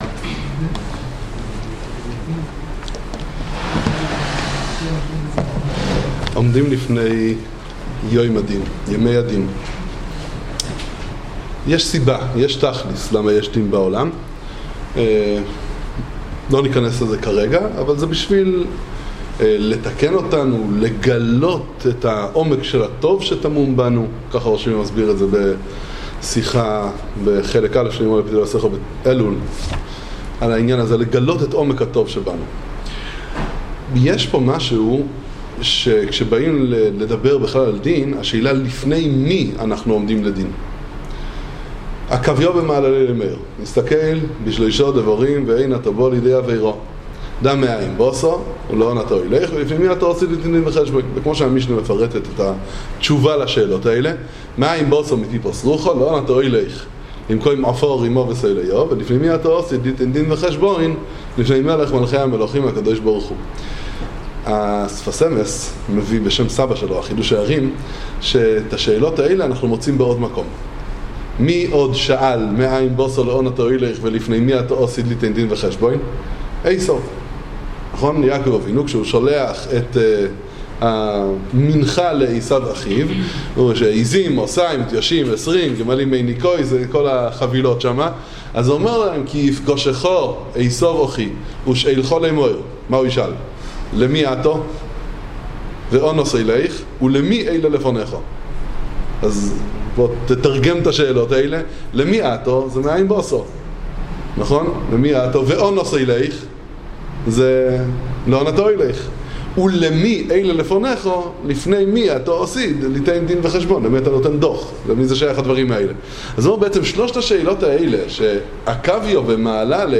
שיחת חיזוק לכבוד הימים הנוראים